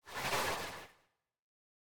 assets / minecraft / sounds / mob / breeze / slide2.ogg
slide2.ogg